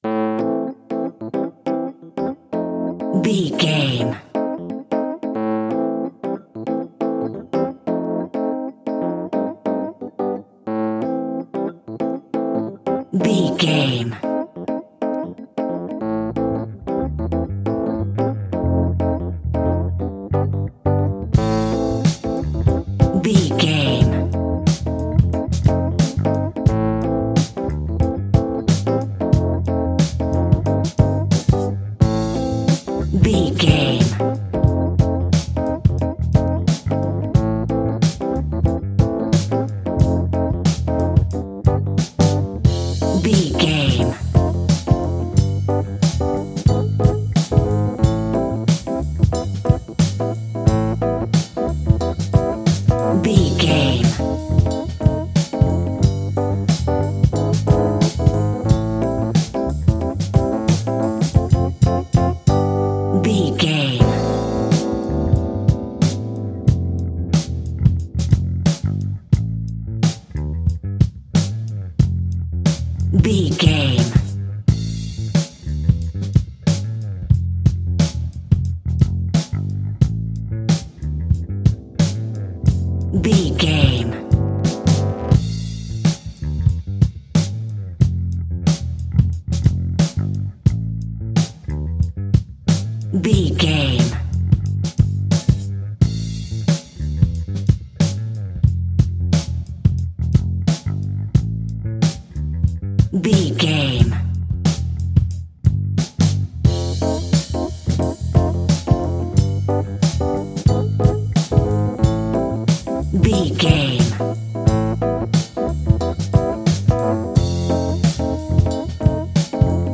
Aeolian/Minor
funky
uplifting
bass guitar
electric guitar
organ
drums
saxophone
groovy